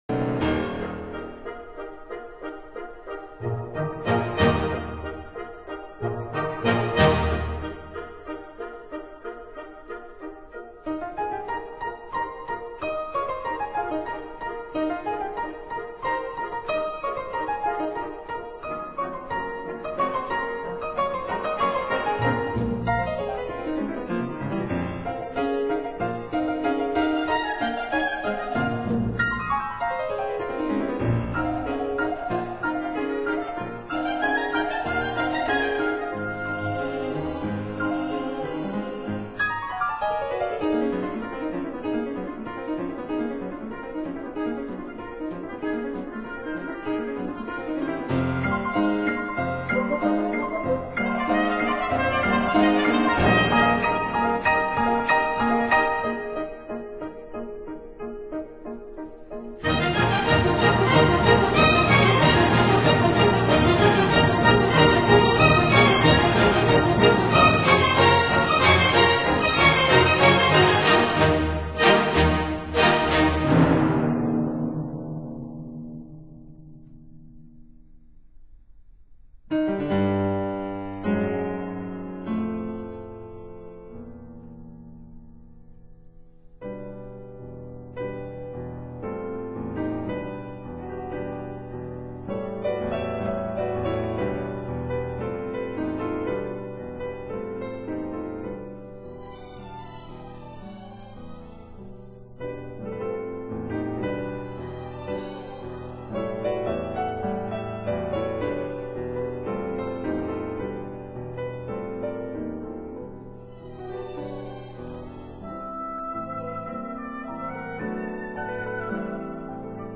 04 Legend for piano and orchestra * [13'05]